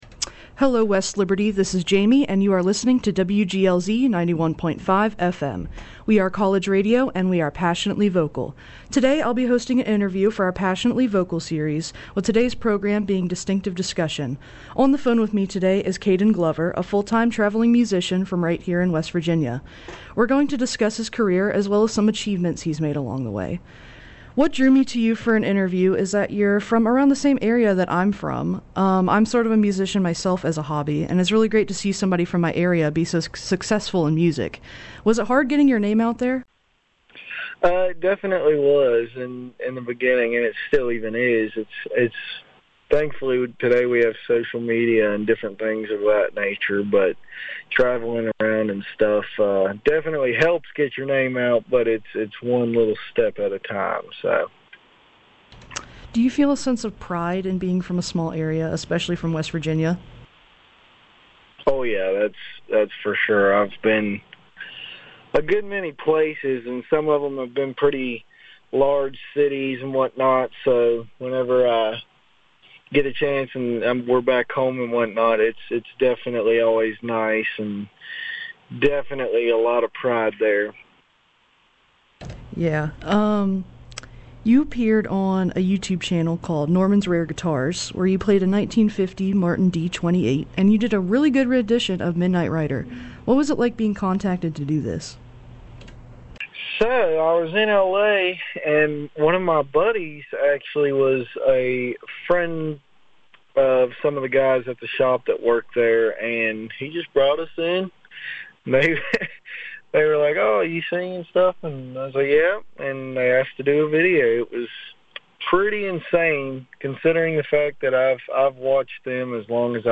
During the interview